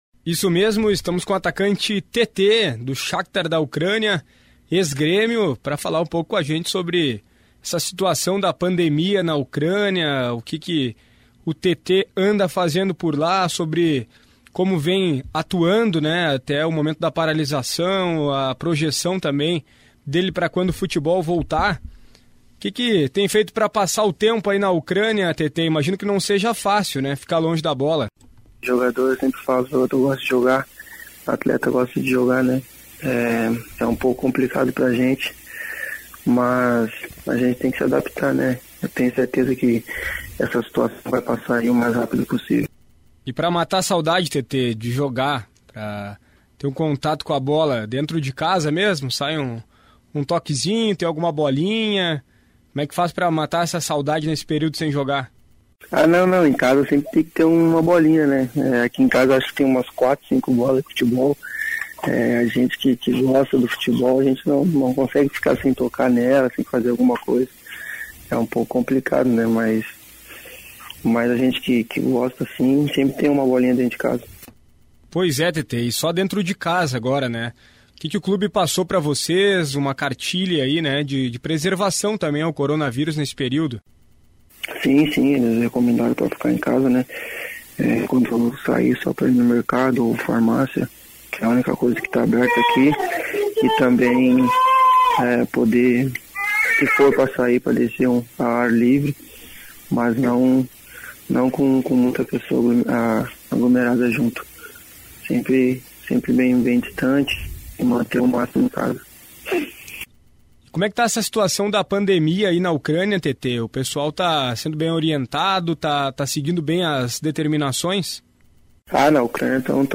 Em entrevista à Rádio Grenal, Tetê comemora adaptação na Ucrânia, mas lamenta saída do Grêmio - Radio Grenal